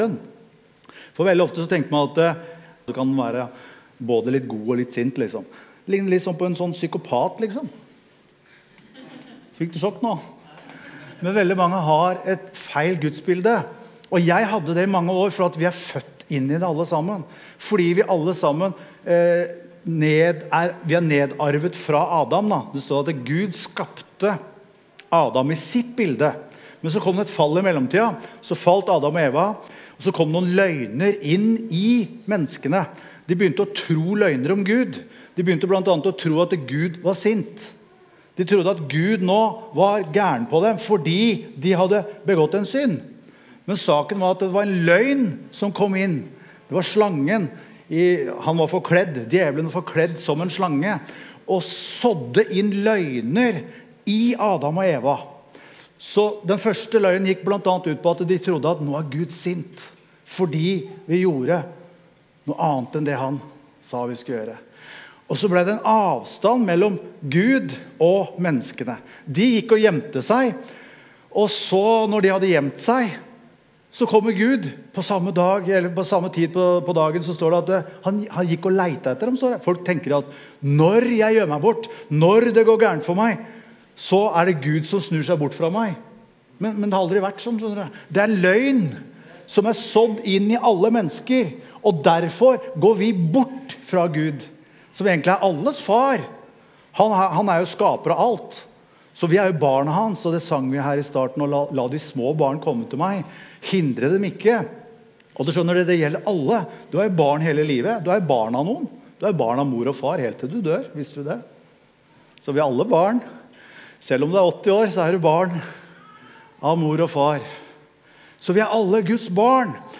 Evangeliehuset Romerike - Gudstjenester